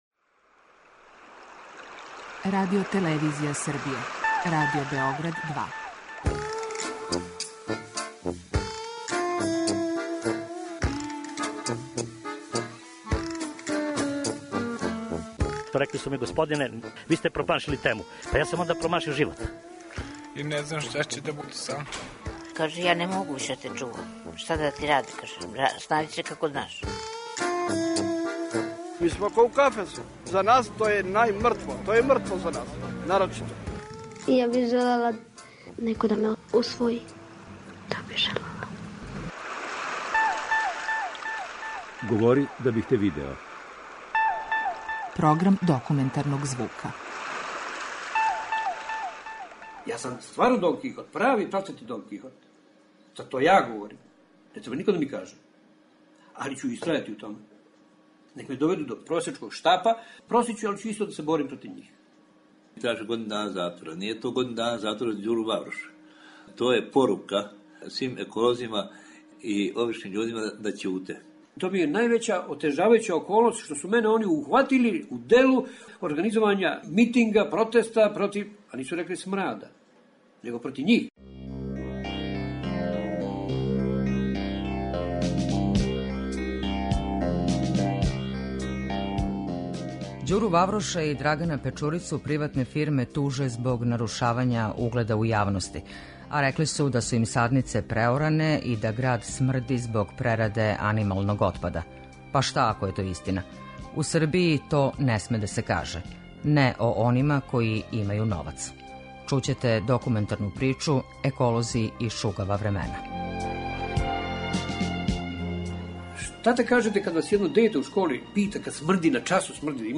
Документарни програм
Чућете документарну причу: "Еколози и шугава времена".